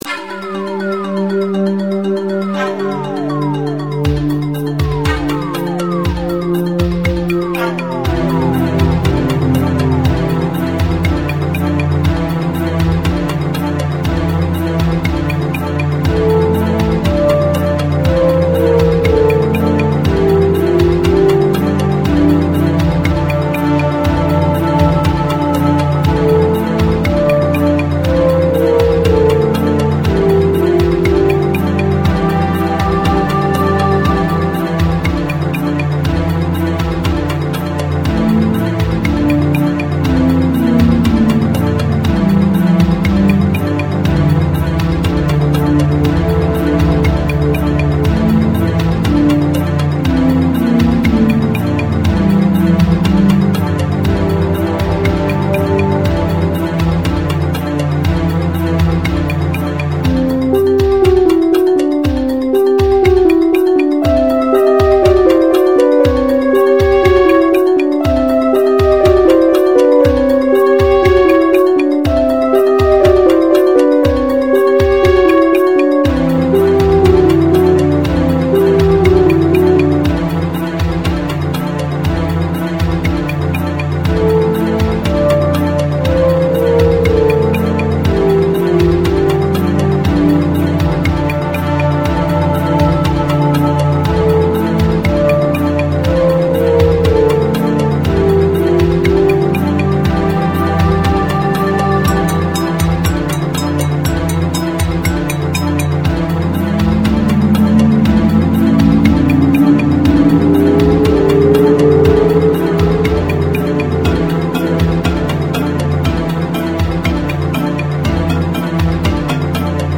It's done mostly on a Yamaha TG33, with an Alesis D4 providing the rhythm track and the Korg DS-8 and Kurzweil K2000 coming in for one sound (the whale-call sound in the middle of the song). This is a really old track, and heavily sequenced - we do much more live, organic stuff now.